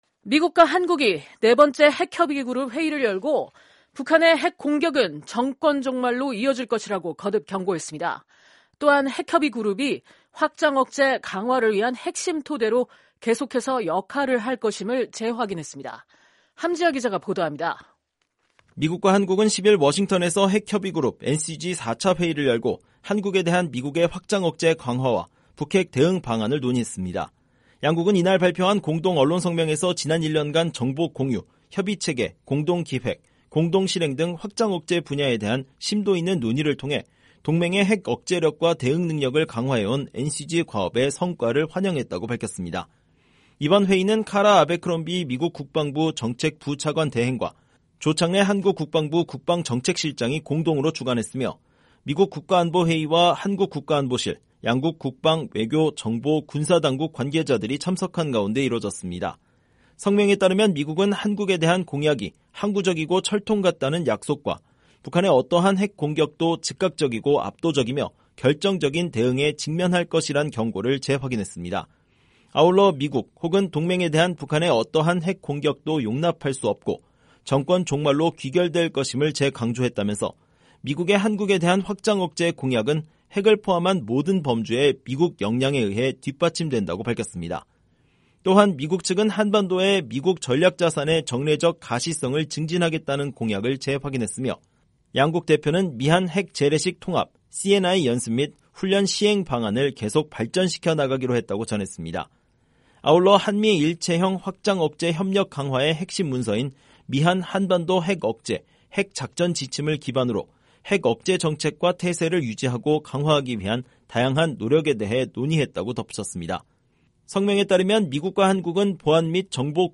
기자가 보도합니다.